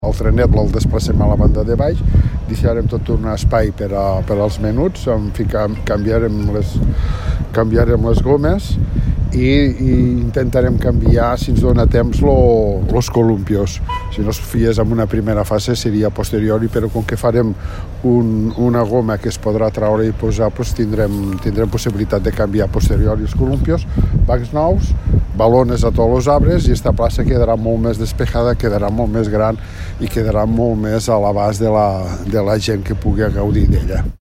El regidor d’Obres i Serveis, Antonio Espuny, explica quins canvis es farà en el parc infantil.